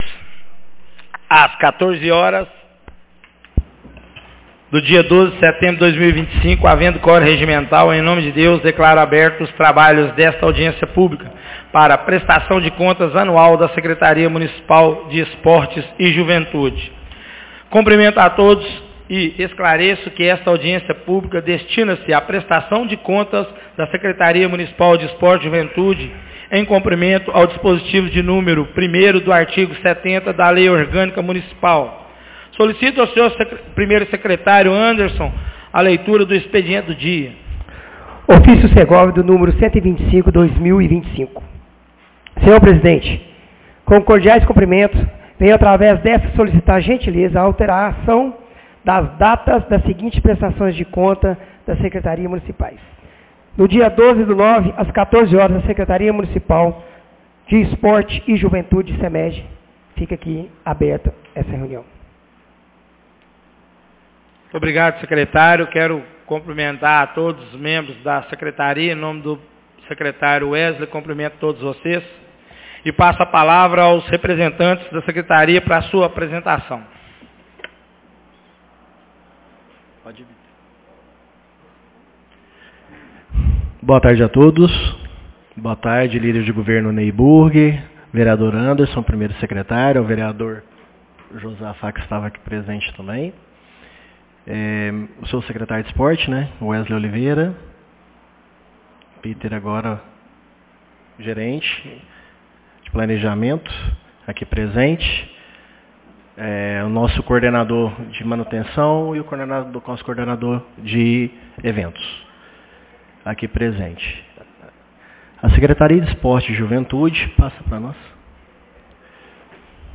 Audiencia publica prestação de contas secretaria de esportes 12 de setembro de 2025